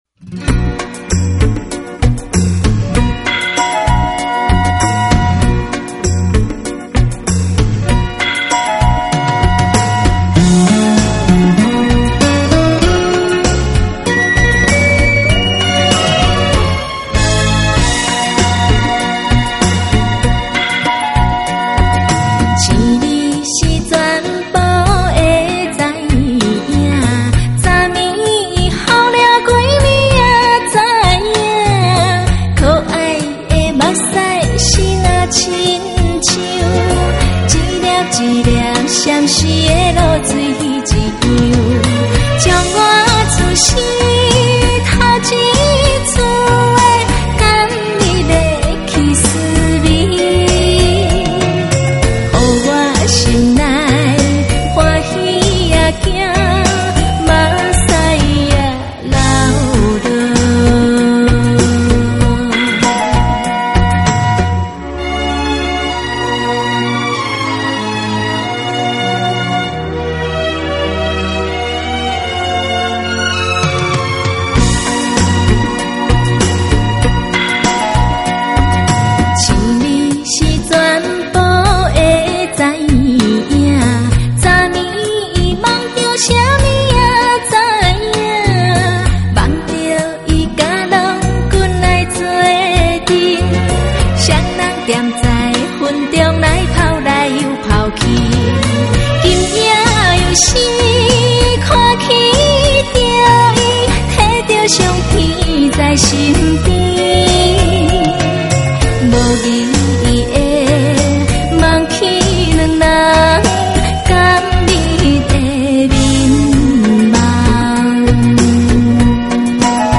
有溫婉甜美的情歌